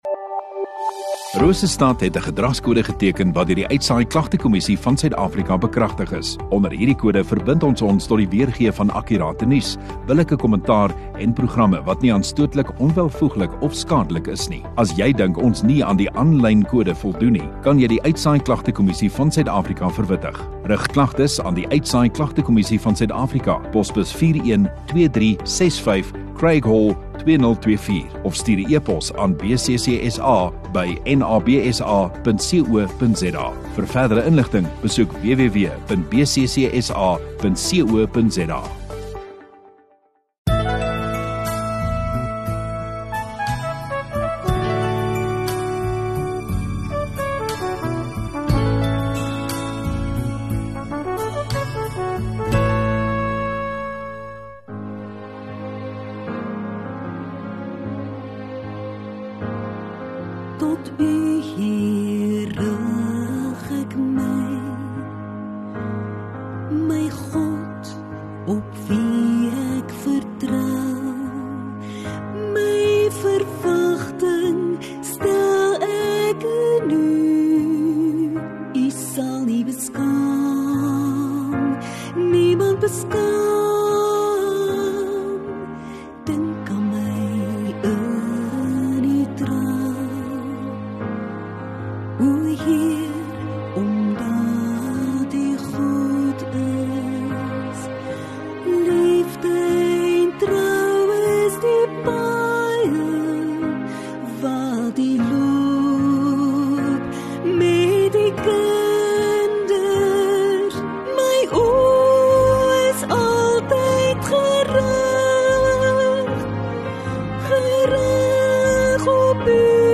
28 Sep Sondagaand Erediens